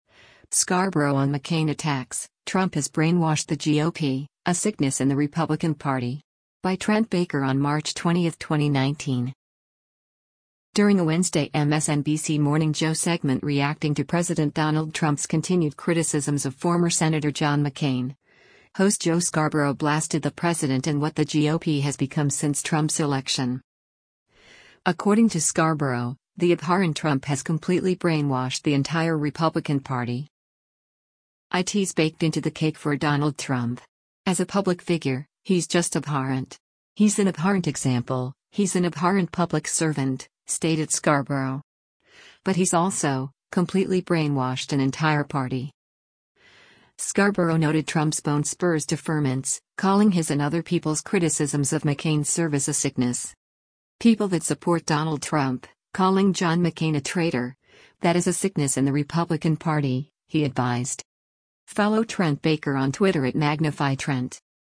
During a Wednesday MSNBC “Morning Joe” segment reacting to President Donald Trump’s continued criticisms of former Sen. John McCain, host Joe Scarborough blasted the president and what the GOP has become since Trump’s election.